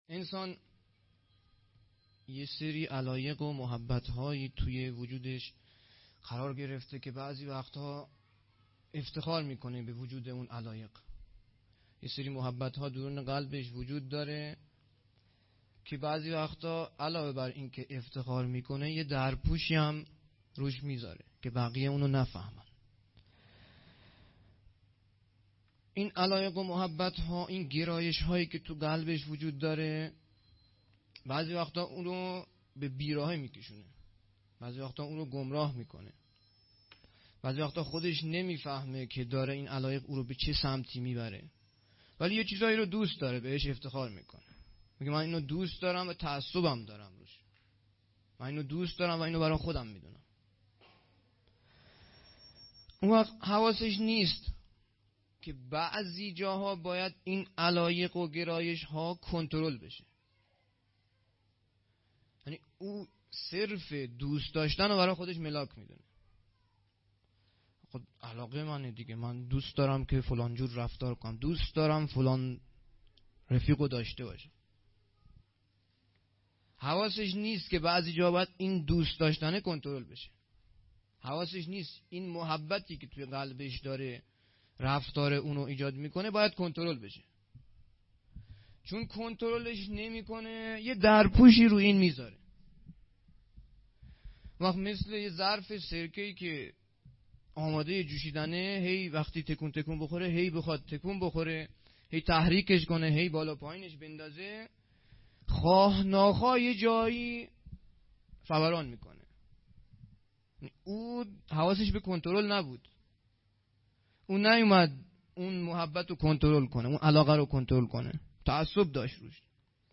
سخنرانی
هیئت دانش آموزی انصارالمهدی(عج)-دارالعباده یزد